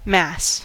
mass: Wikimedia Commons US English Pronunciations
En-us-mass.WAV